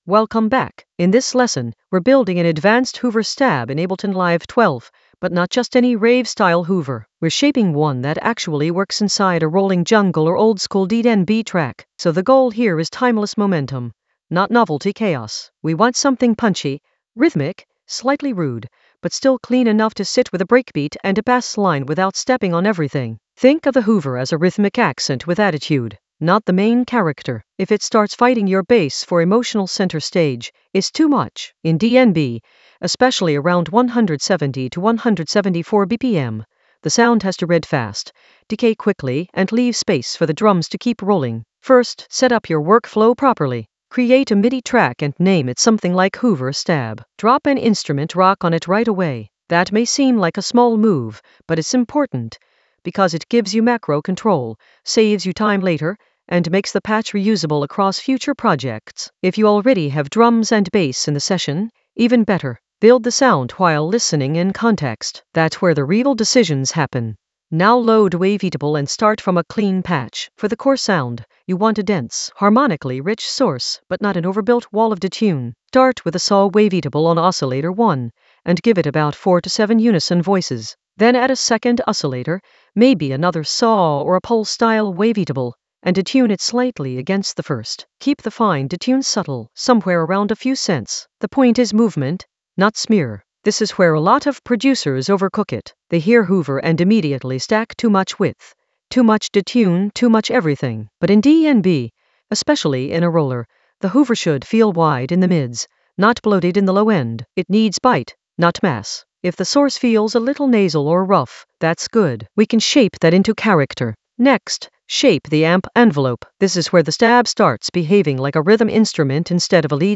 An AI-generated advanced Ableton lesson focused on Hoover stab in Ableton Live 12: design it for timeless roller momentum for jungle oldskool DnB vibes in the Workflow area of drum and bass production.
Narrated lesson audio
The voice track includes the tutorial plus extra teacher commentary.